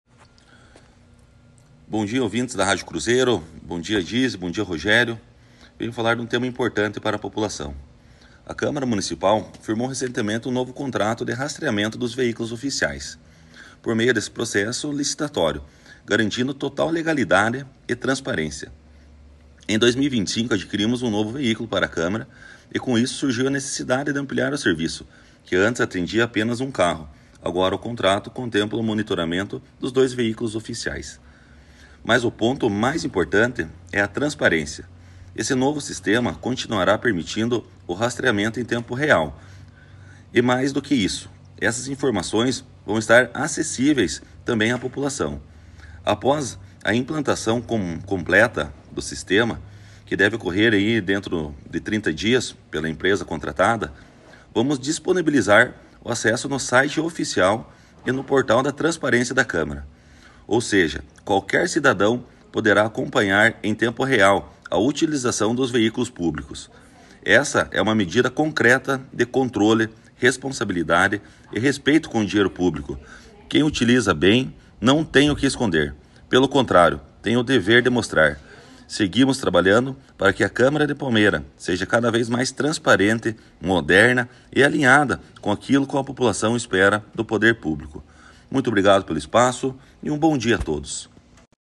O presidente da Câmara Municipal, vereador Diego Zanetti (Republicanos), conversou com o jornalismo da Cruzeiro sobre um novo contrato firmado pelo Legislativo.
Acompanhe o que disse o presidente da Câmara sobre o assunto: